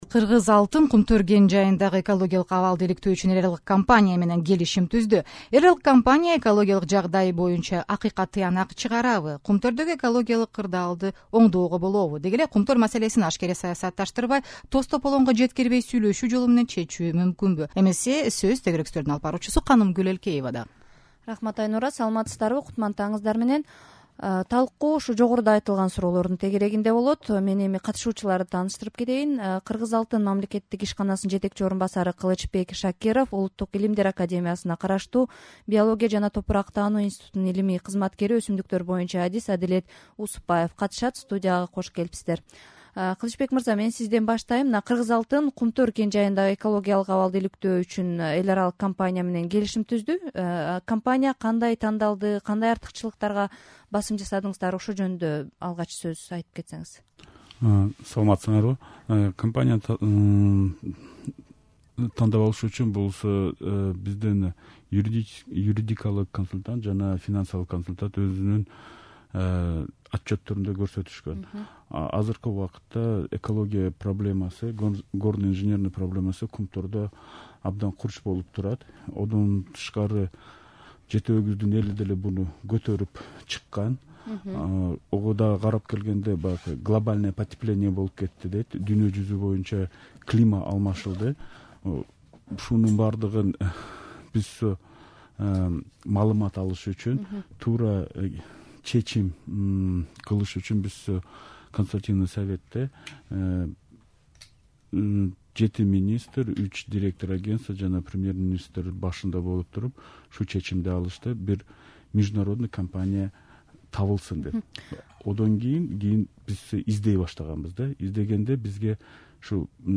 Кумтөр тууралуу талкуу (1-бөлүк)